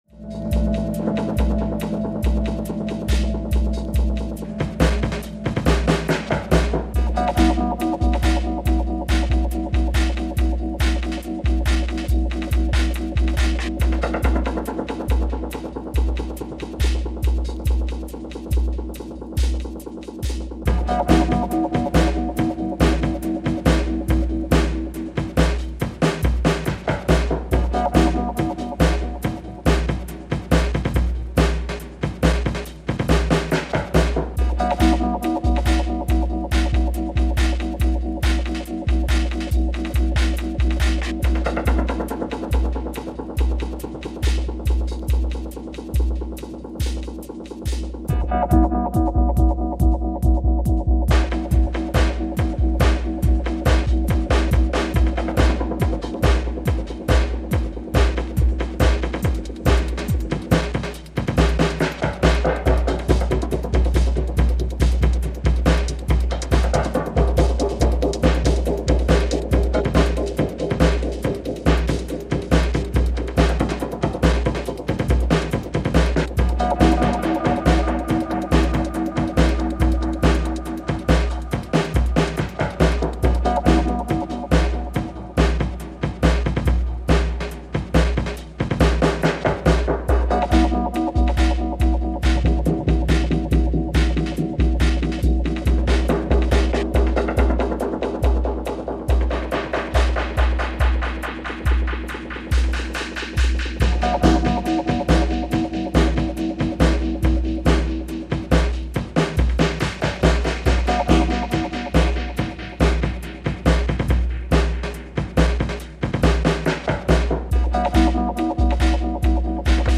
electronic music releases